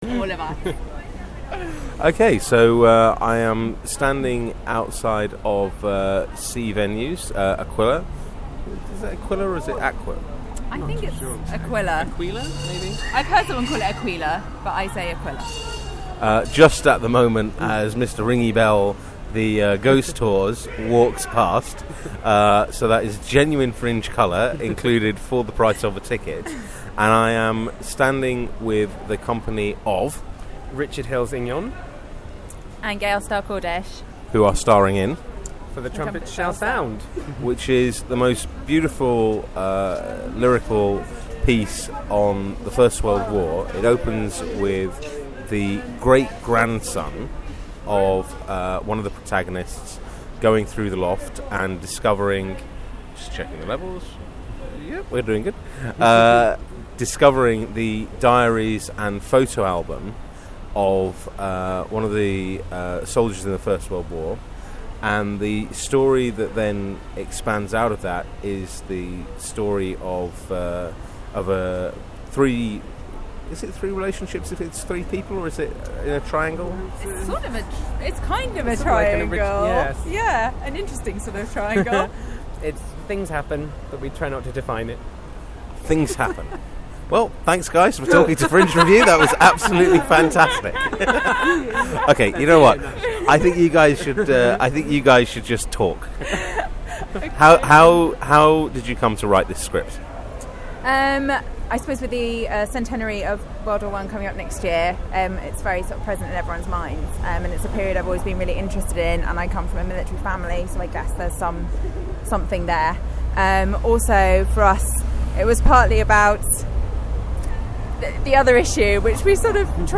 Audio Interviews, Edinburgh Festivals 2013, Edinburgh Fringe 2013